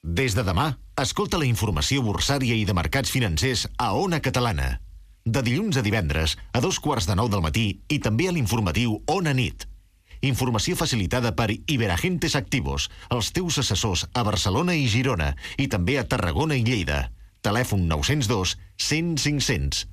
Promoció de l'espai d'informació bursària i de mercats financers